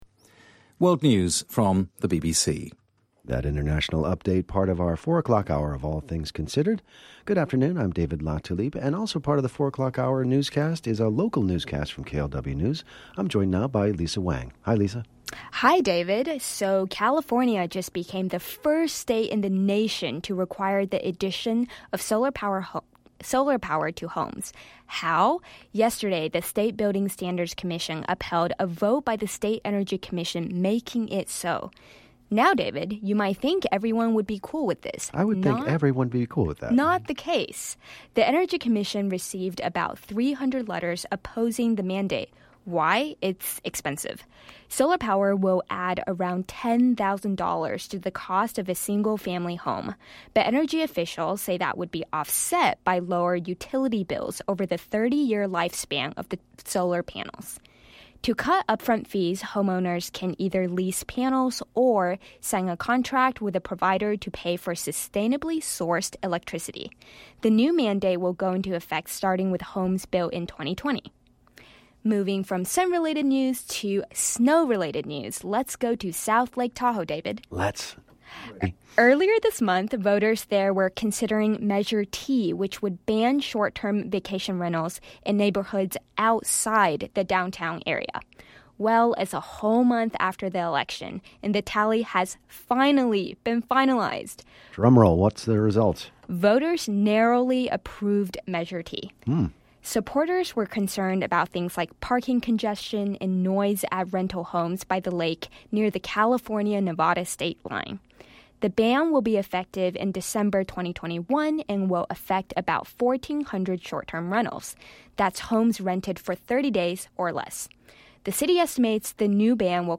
First off, below are some of the first newscasts to be read live by this year’s Audio Academy fellows.